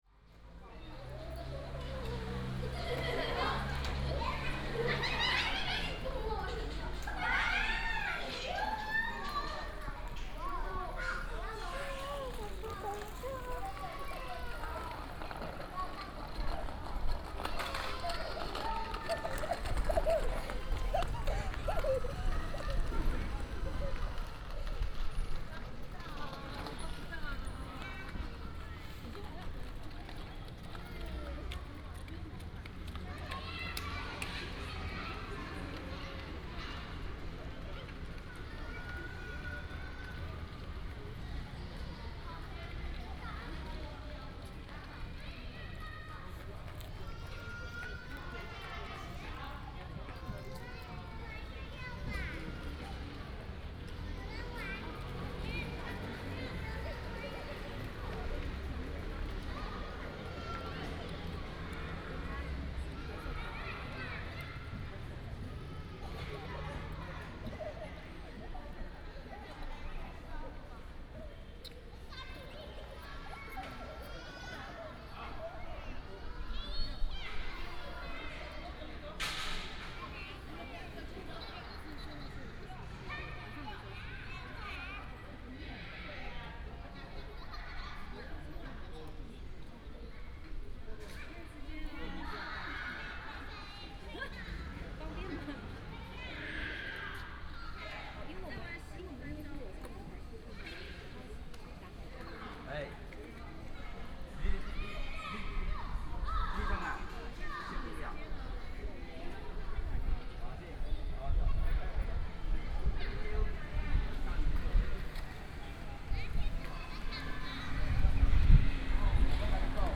Best with Headphone
Sony PCM D100+ Soundman OKM II